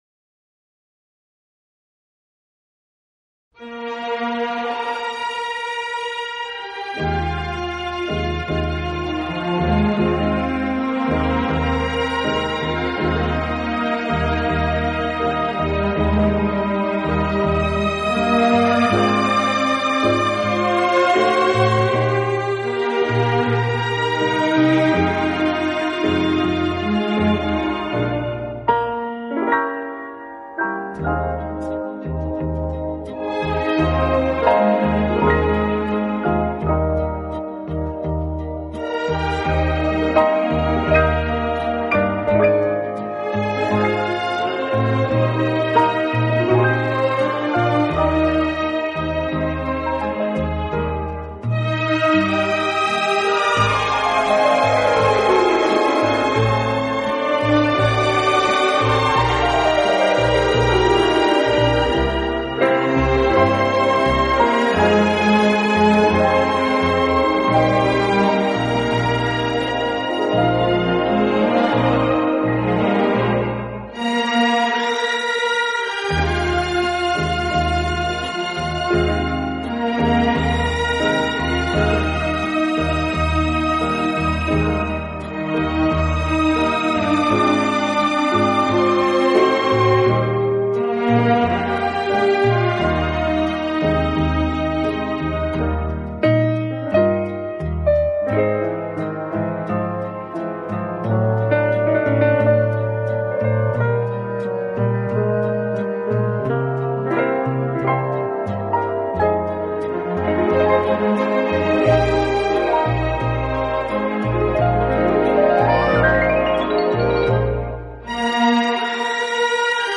轻音乐专辑